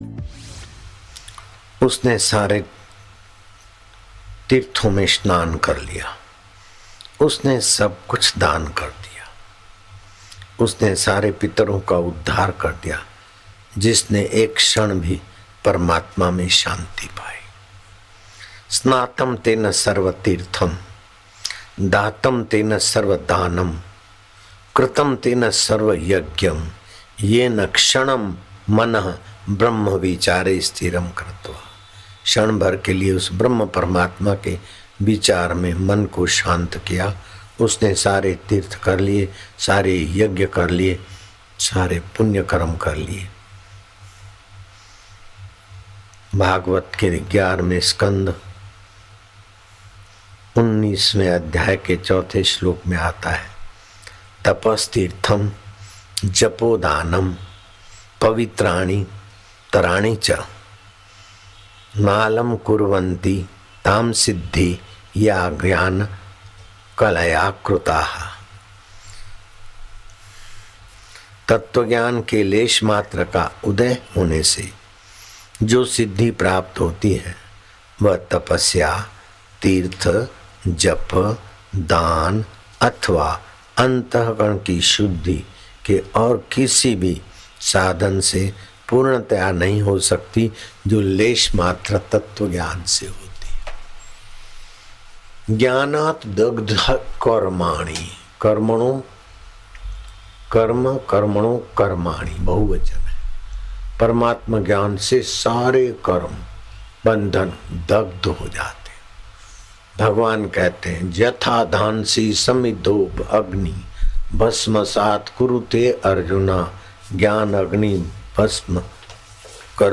Audio Satsang MP3s of Param Pujya Sant Shri Asharamji Bapu Ashram